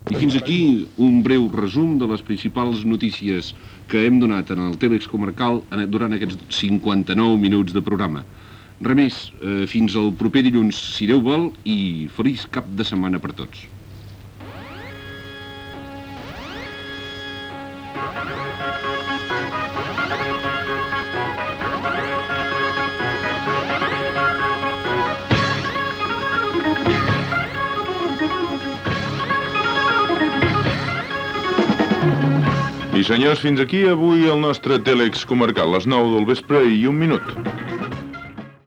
Comiat del programa Gènere radiofònic Informatiu